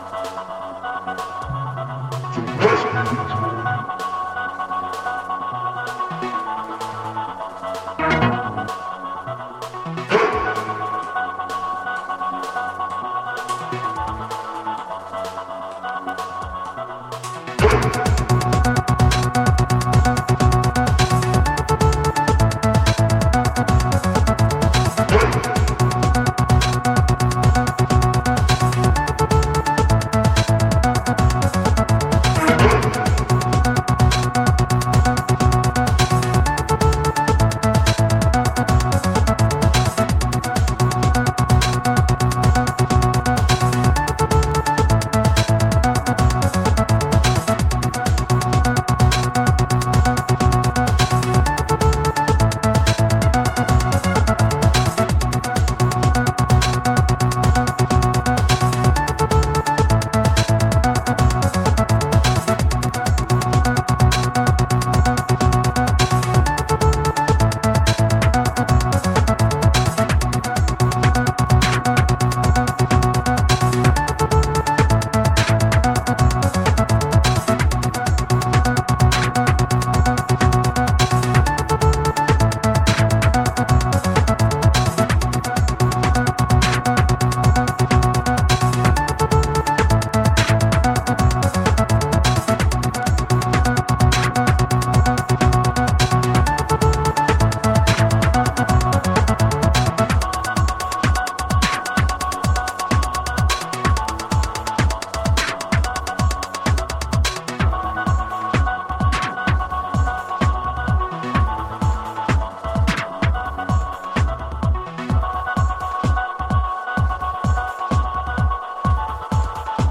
Genre Drum n Bass , Electro , House , Techno